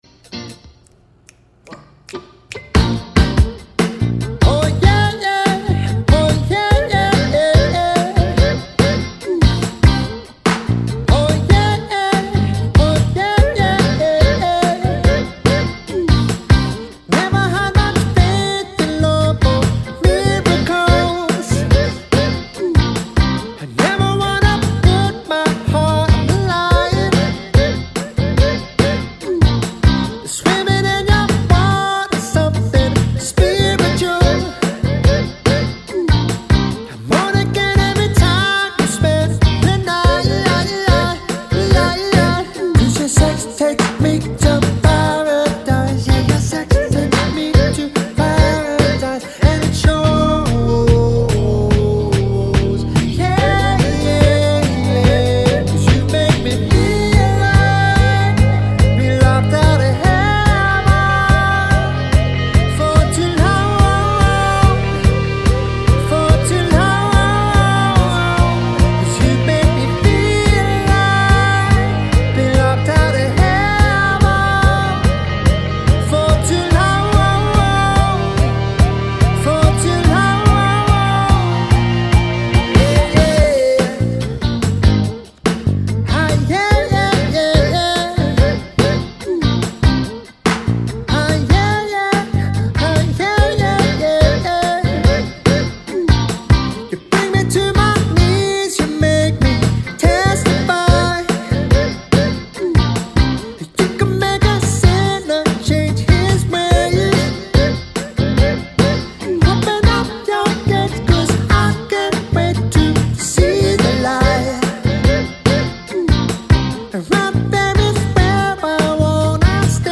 When looking for a professional cover band
trained jazz musicians